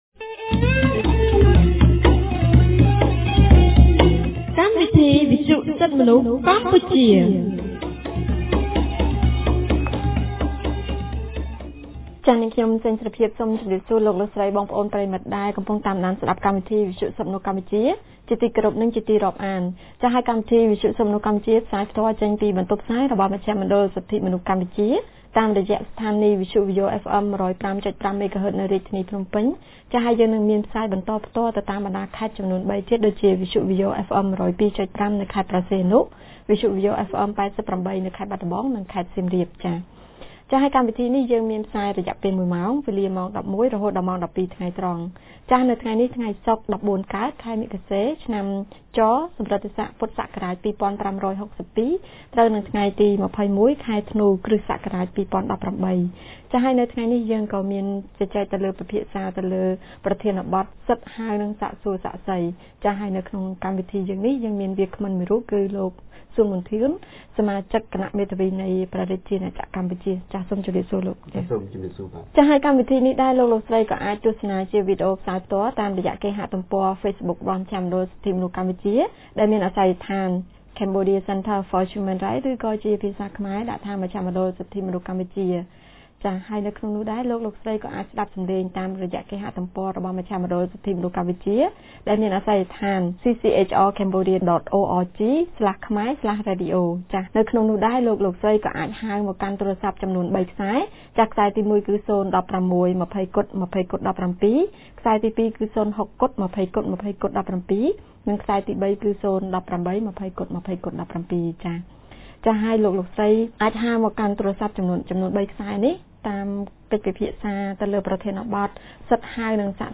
On 21 December 2018, CCHR’s Fair Trial Rights Project (FTRP) held a radio program with a topic on Right to call and examine witness.